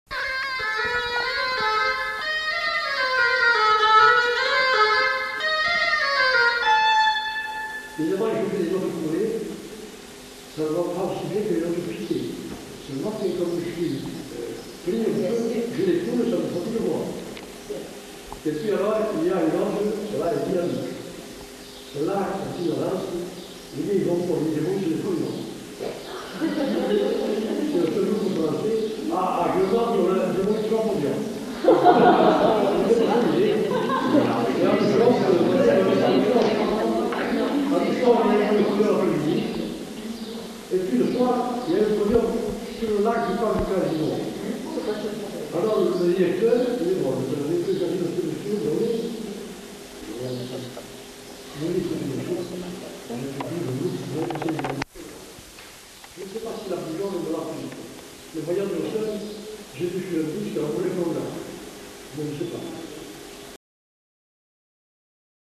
Aire culturelle : Couserans
Genre : morceau instrumental
Instrument de musique : hautbois
Danse : traversée
Notes consultables : Fragment. Commentaires du musicien.